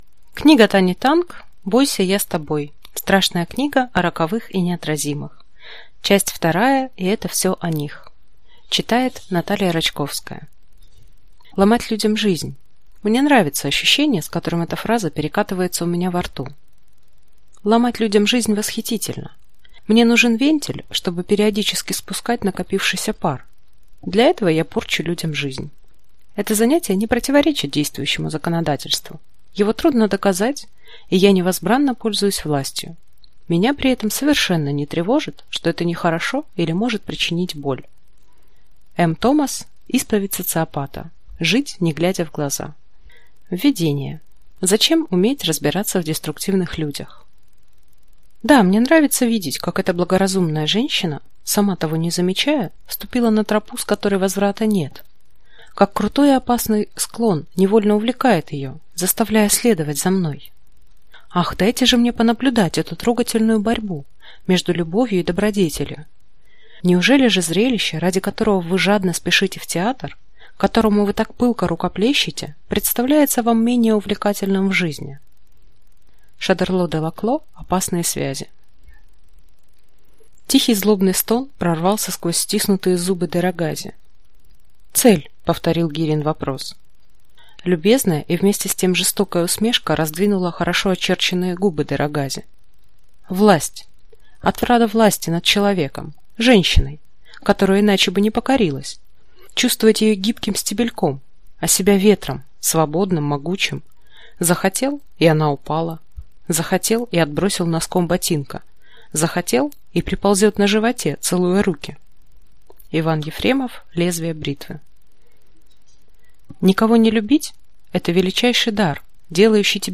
Аудиокнига Бойся, я с тобой. Страшная книга о роковых и неотразимых. Часть 2: и это все о них | Библиотека аудиокниг